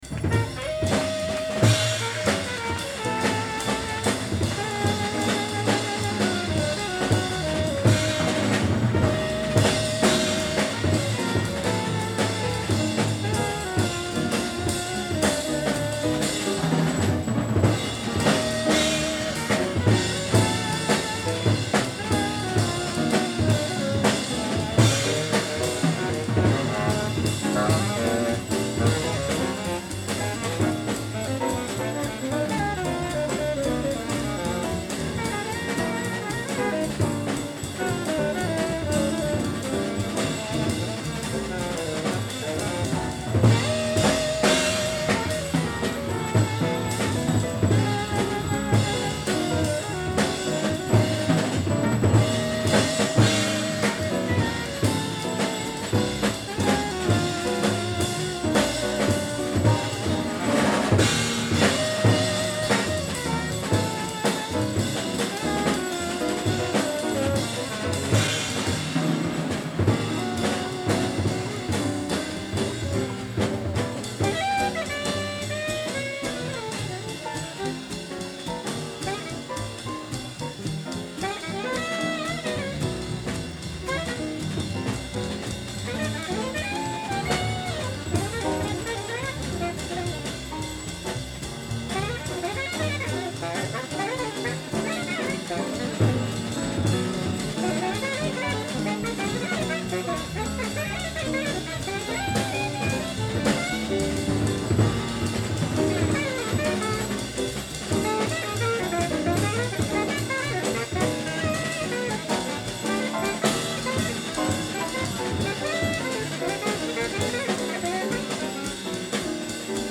2021/ژانر : Jazz/زمان